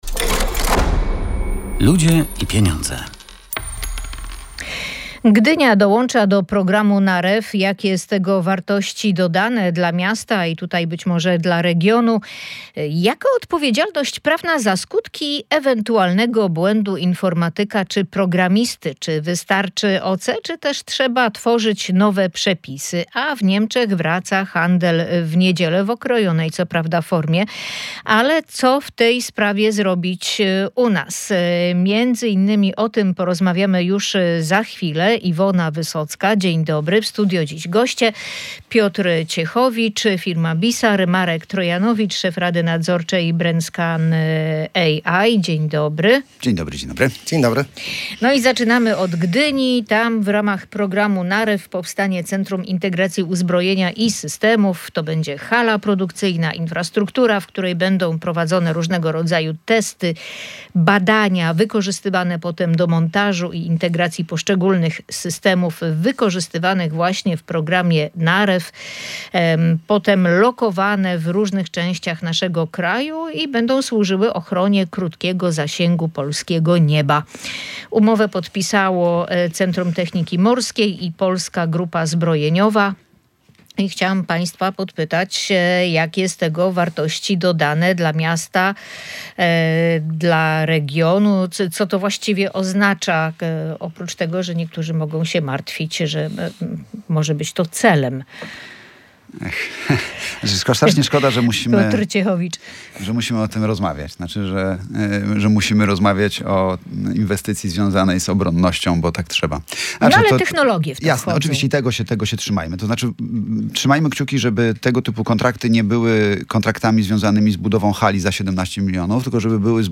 Goście audycji "Ludzie i Pieniądze"